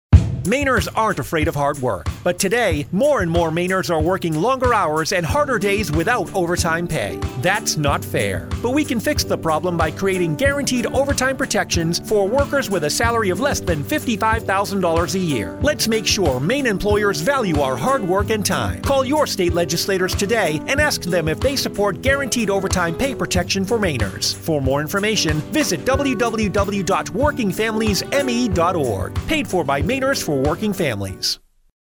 New radio ads urge Maine legislators to support guaranteed overtime protections for Maine workers